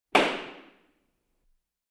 На этой странице собраны разнообразные звуки, связанные с судебными процессами: от характерных ударов молотка судьи до шума зала заседаний.
Судейский молоток ударил